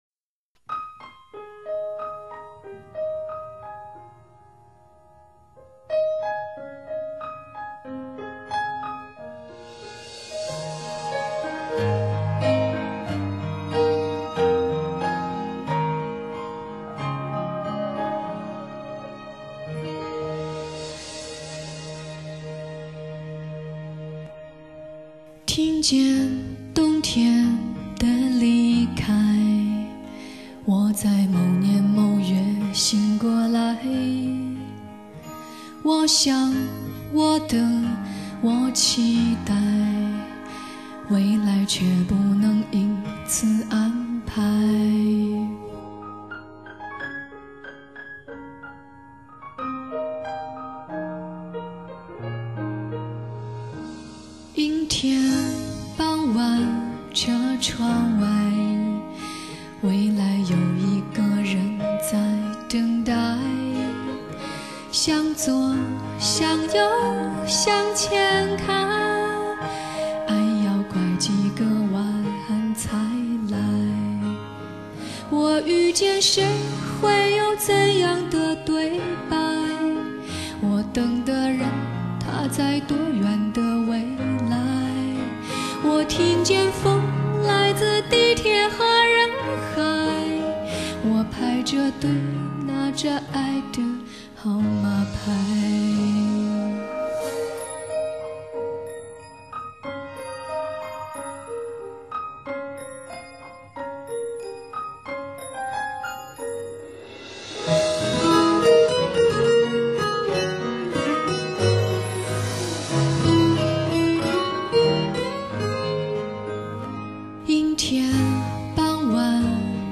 翻唱